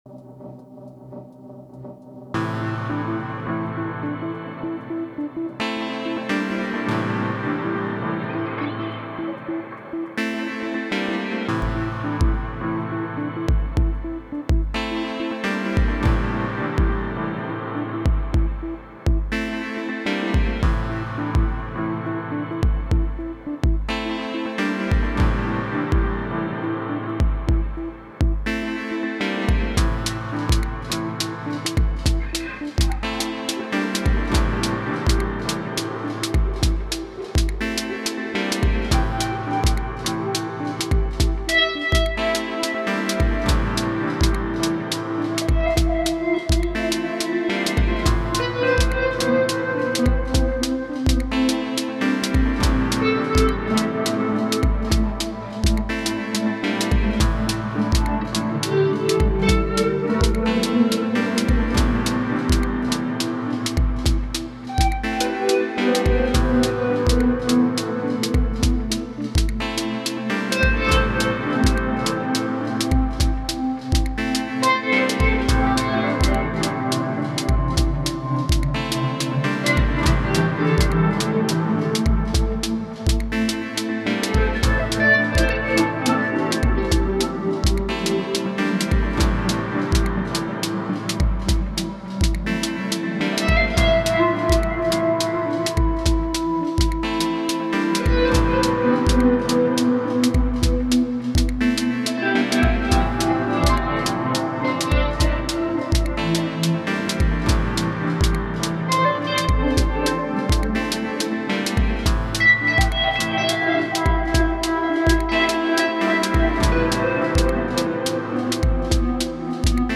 Number 12! 0-Coast into Quintessence for chords loop, Volcas for percussion, psychedelic guitar noodling on top with a freezing pitched down Particle every now and then and lots of verb 'n delay.